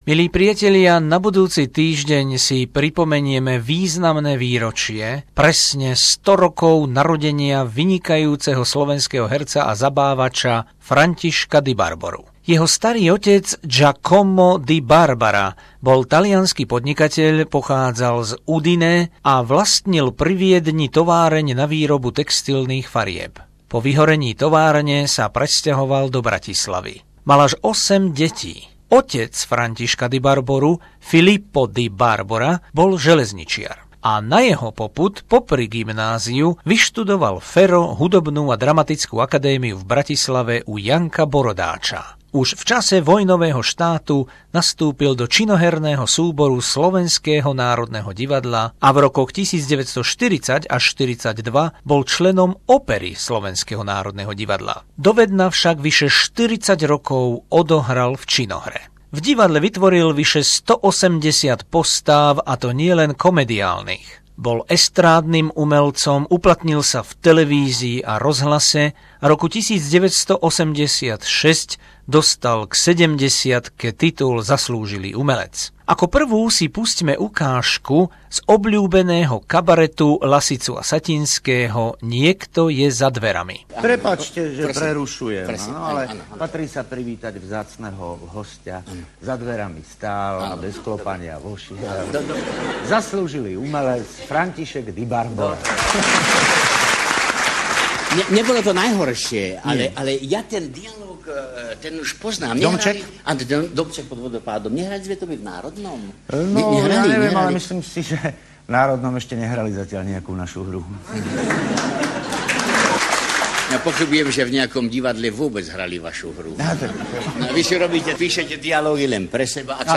Zvukový portrét vynikajúceho slovenského herca a hokejistu Františka Dibarboru, legendárneho zabávača, vnuka talianskeho prisťahovalca do Bratislavy Giacoma Di Barbaru, ktorý sa narodil presne pred 100 rokmi